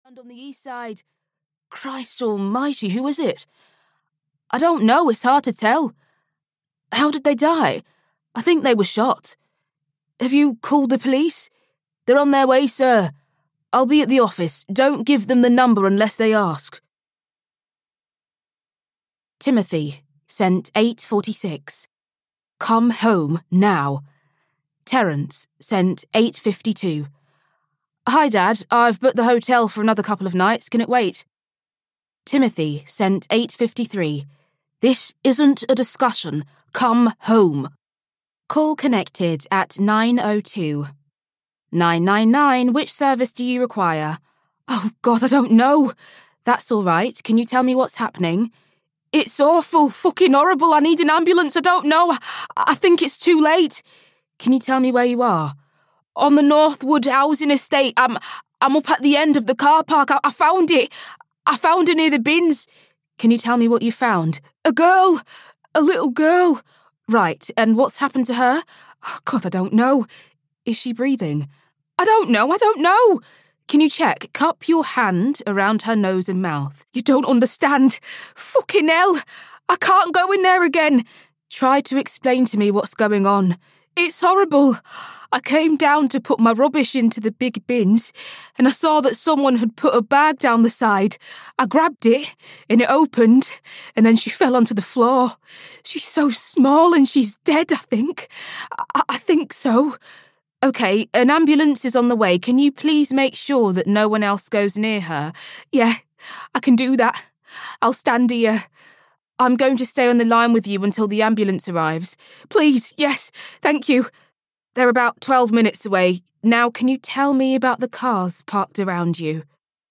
Shot in the Dark (EN) audiokniha
Ukázka z knihy